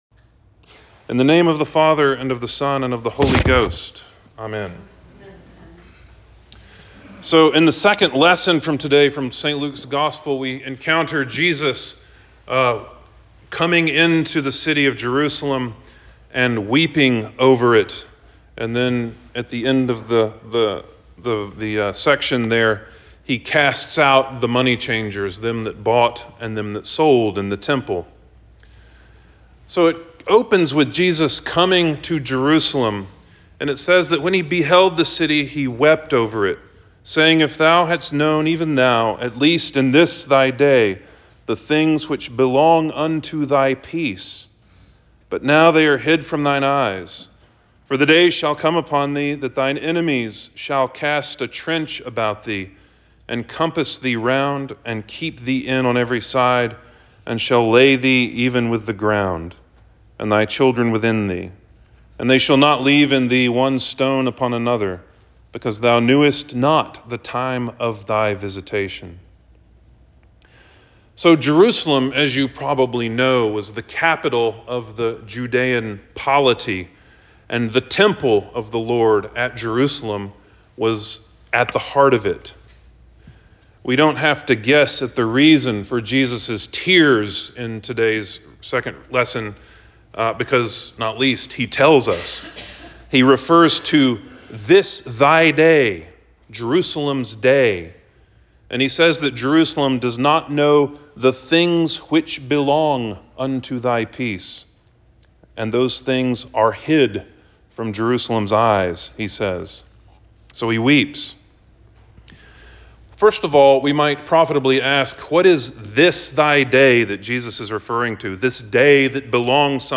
Trinity X Sermon 08.13.23